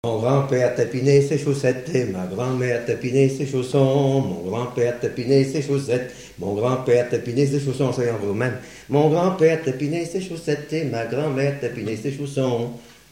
Mémoires et Patrimoines vivants - RaddO est une base de données d'archives iconographiques et sonores.
mazurka jouée à la fin du quadrille
danse : mazurka